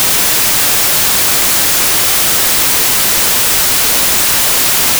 Noise,
Noise.wav